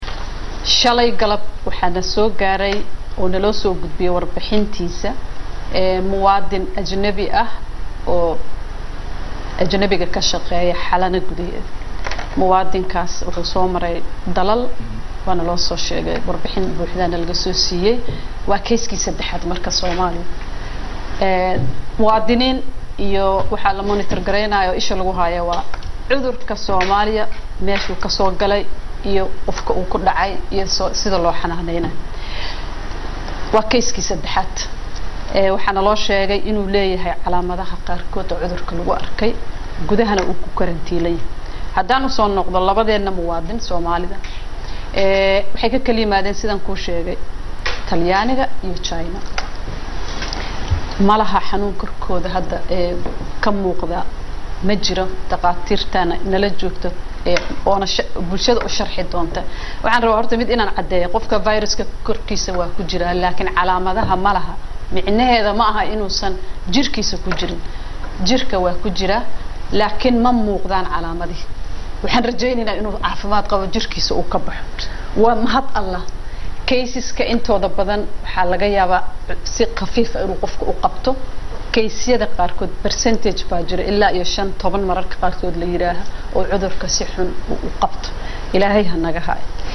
Wasiirka Wasaaradda caafimaadka xukumadda Federaalka Soomaaliya Dr. Fowsiyo Abiikar Nuur oo ka qeyb-gashay Barnaamijka Kulanka todobaadka ee ka baxa warbaahinta
COD-WASIIRKA-CAAFIMAADKA-.mp3